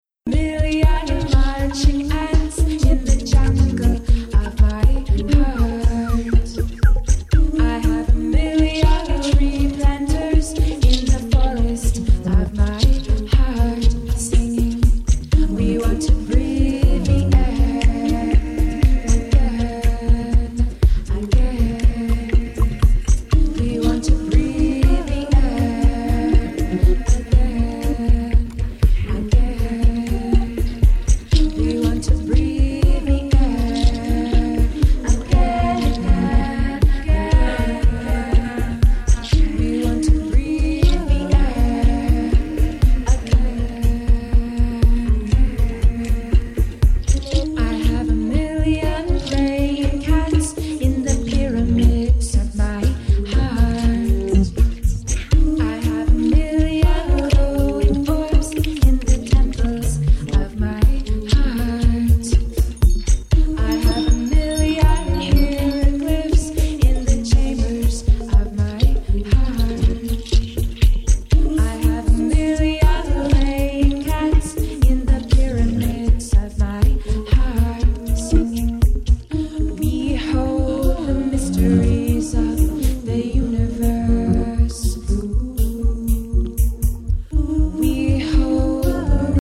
a superb exotically hypnotic contextual take